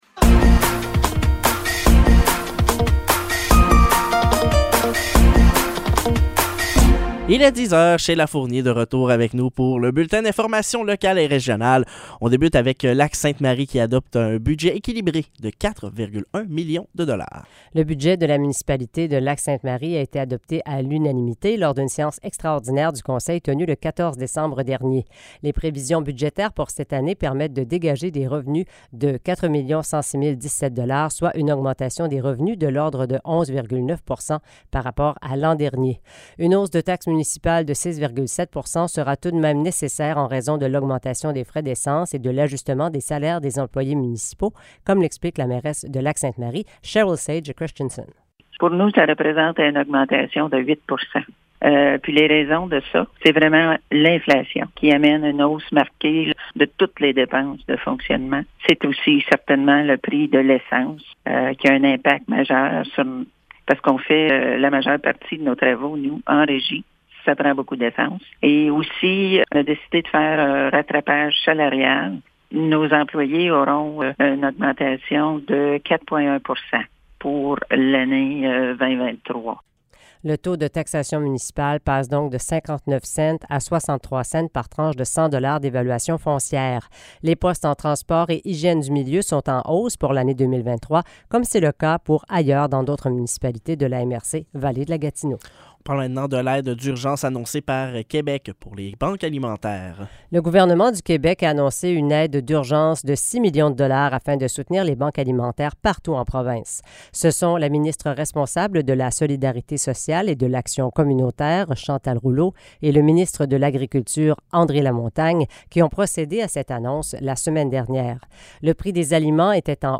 Nouvelles locales - 19 décembre 2022 - 10 h